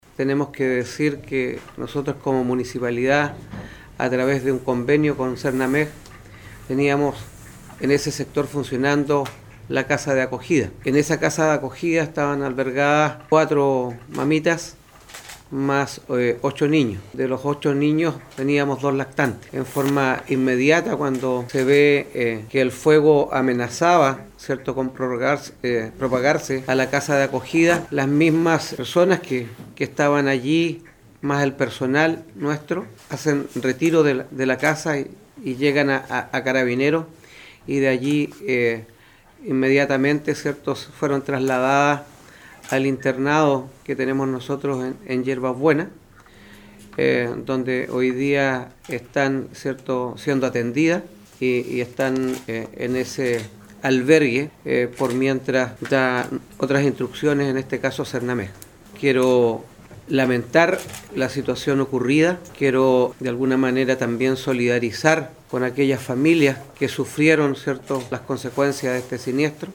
El alcalde de Ancud Carlos Gómez expresó que las mamás residentes de la casa de acogida debieron evacuar junto a sus hijos el inmueble que también se vio afectado por el incendio.